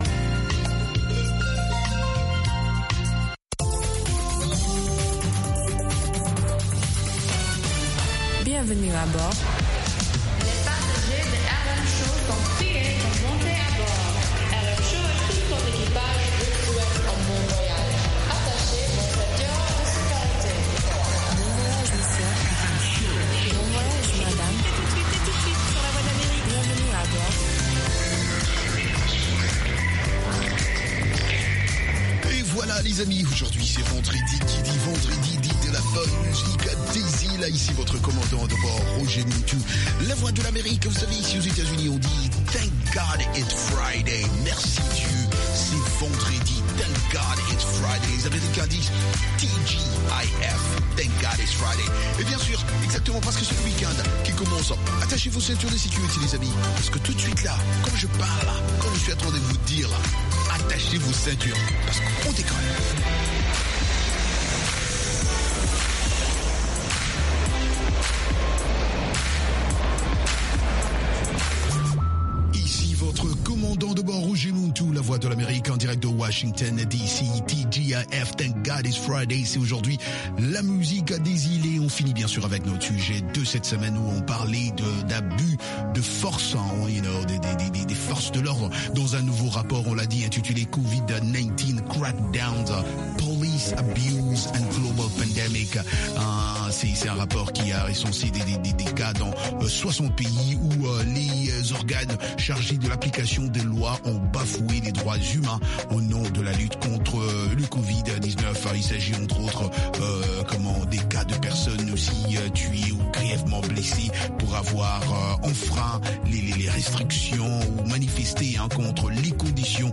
Ecoutez toute la musique des îles, Zouk, Reggae, Latino, Soca, Compas et Afro, et interviews de divers artistes